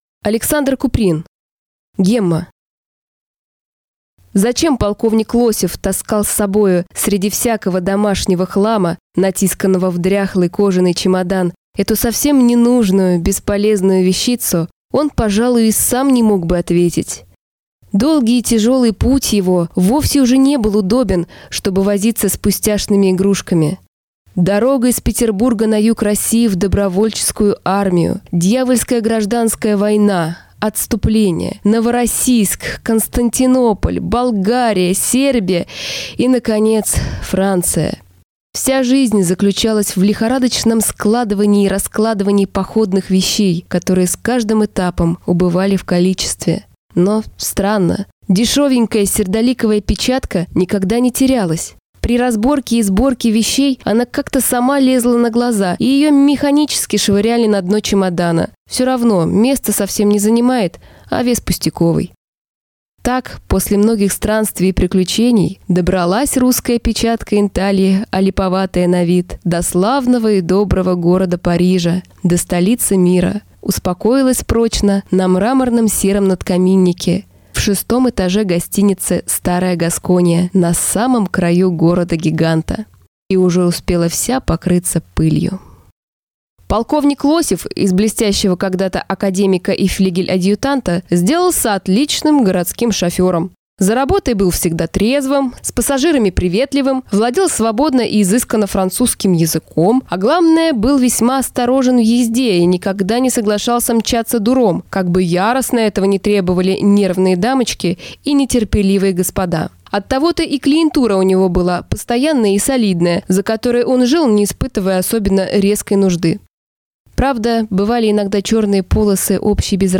Aудиокнига Гемма